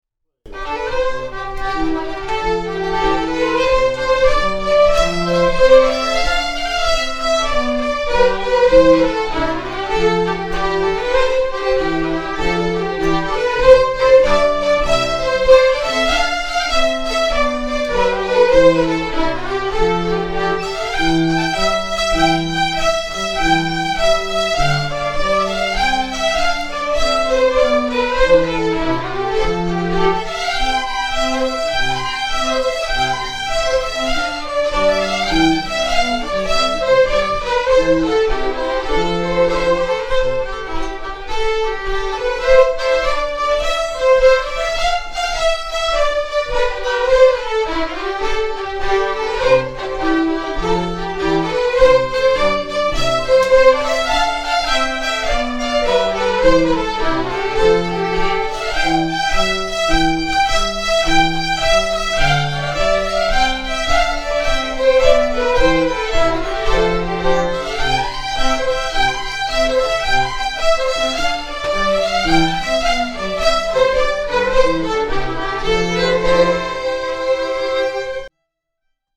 Traditional Scottish Fiddle Music